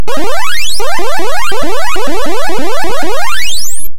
描述：任天堂娱乐系统风格的哔哔声在MaxMSP中生成
Tag: 哔哔声 芯片 计算机 电子 比赛中 LOFI 任天堂 复古 合成